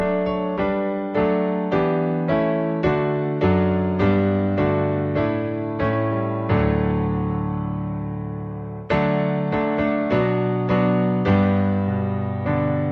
• sample-2.mp3 is the sample I modified in Audacity 2.0.0 by lowering the pitch by two half-steps - you will notice the strong echo (reverberation).
I don’t. The second cut just sounds like the original pitched downward. There is compression damage on that sustained note about 2/3 of the way down, but you can cure that by not using MP3 any more.
I hear it, particularly between 7 and 9 seconds where it sounds like a rhythmic rumble.